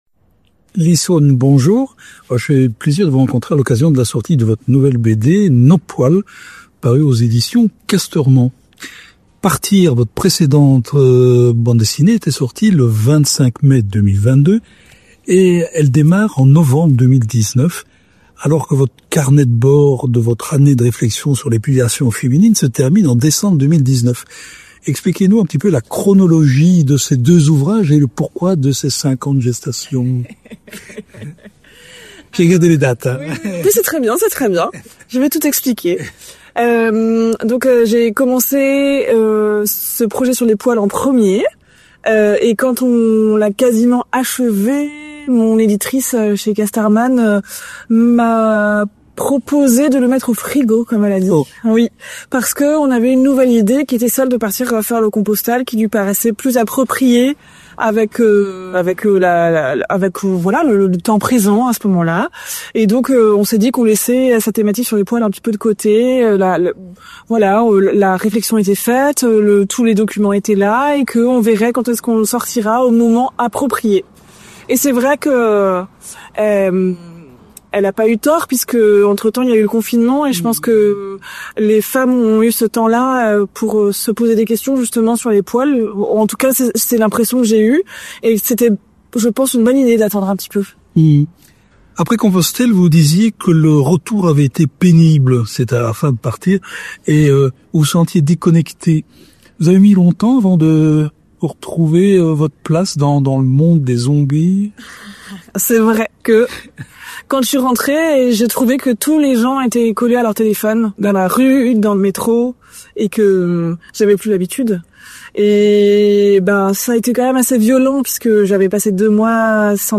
Rencontre. On y parle de diktat du glabre féminin, d’écoféminisme, de SIF, de déforestation, de chatte, de zombies, de cancer, de Rosalie… (Édition Casterman)